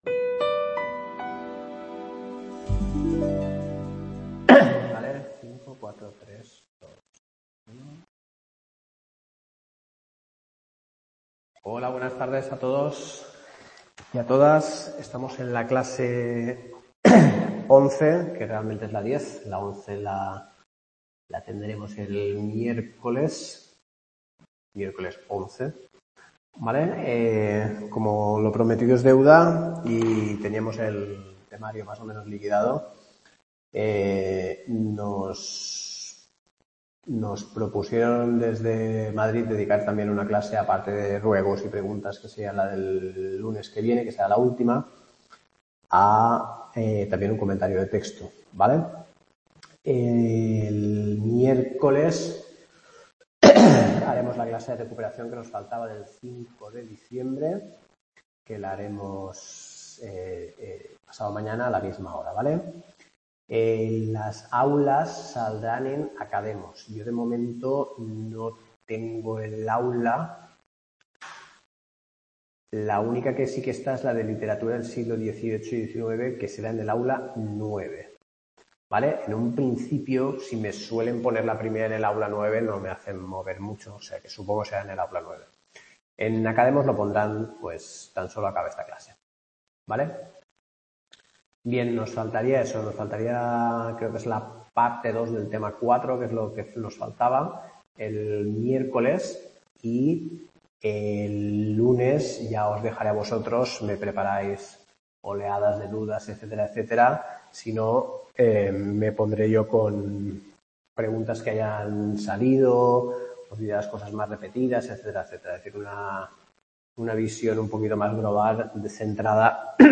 Clase Literatura Medieval11 La Celestina Comentario | Repositorio Digital